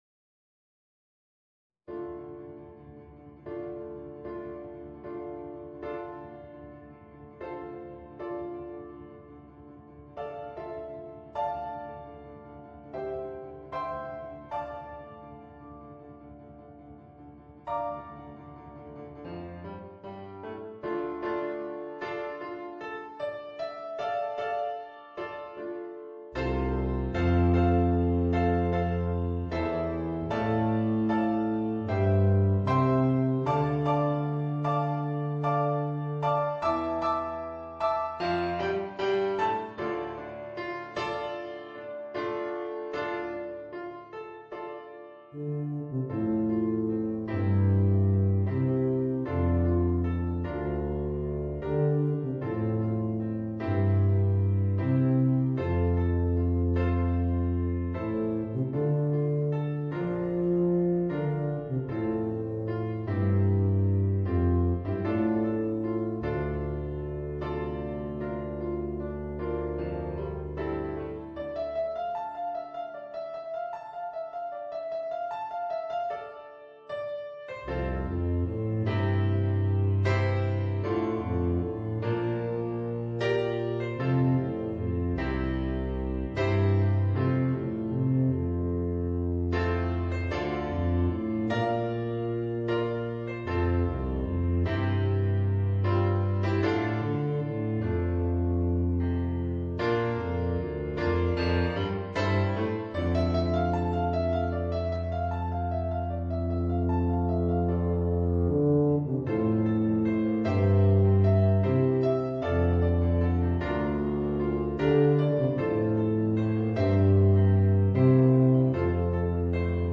Voicing: Tuba and Piano